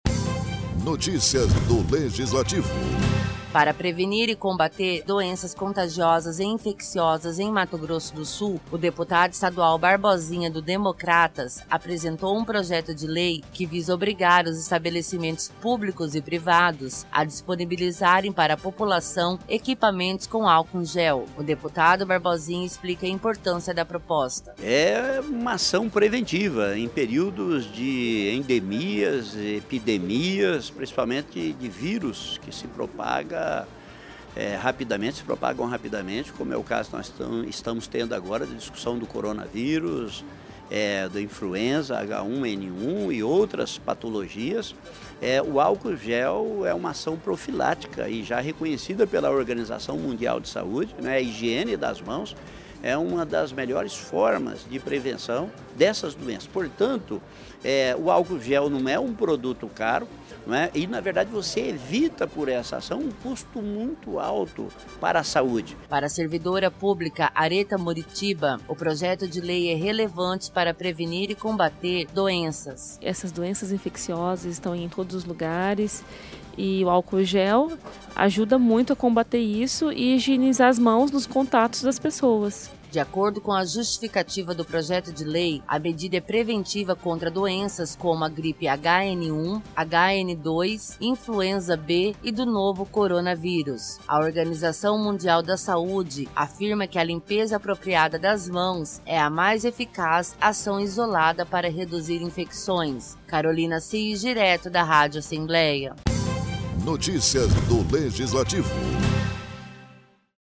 Locução e Produção: